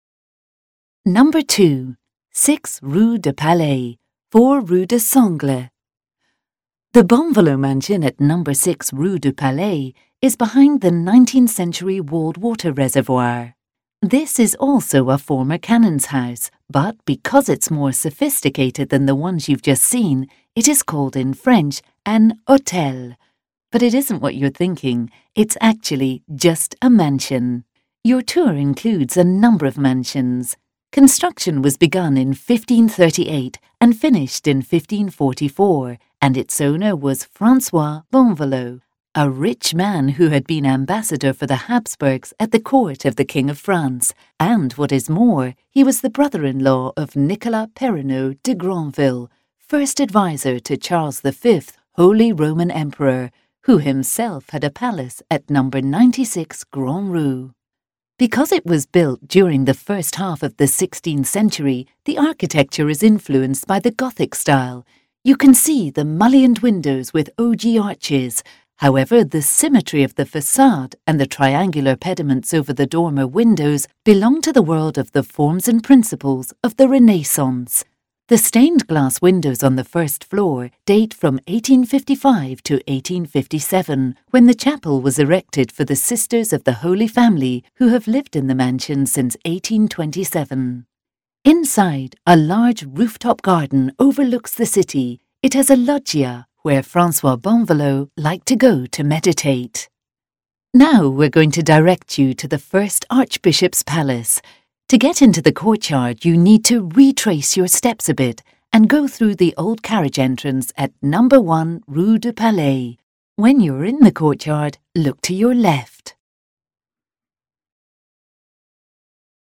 Ecouter l'audio guide